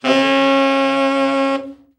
Index of /90_sSampleCDs/Giga Samples Collection/Sax/TENOR VEL-OB